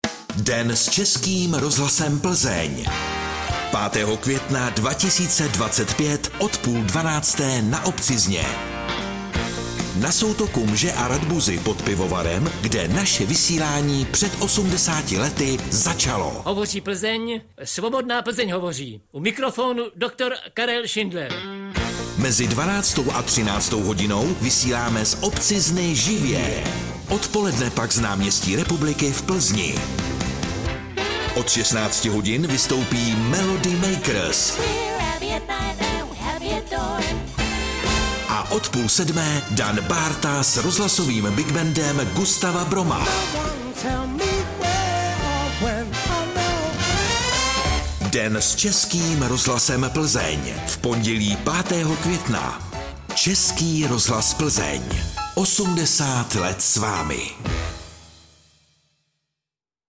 Zprávy pro Plzeňský kraj: 80. výročí s námi oslaví Melody Makers, Rozhlasový Big Band Gustava Broma i Plzeňská filharmonie - 21.04.2025